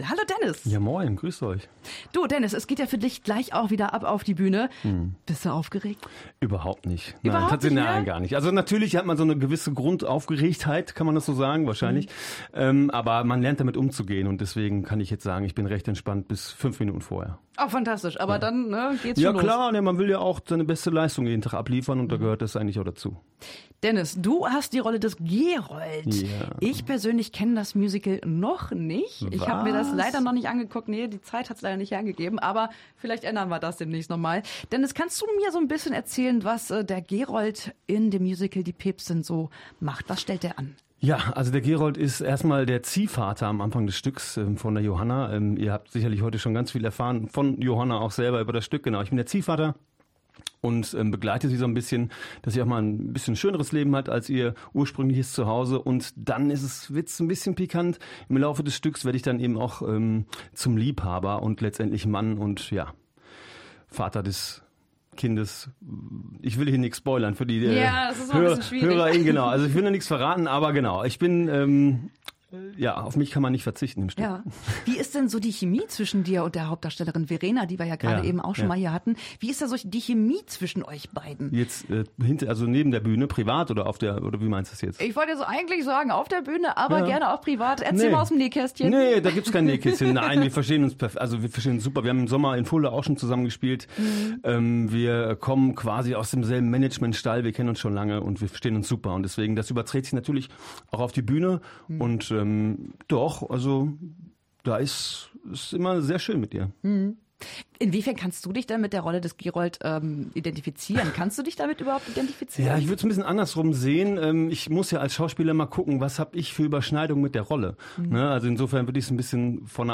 Live-Interview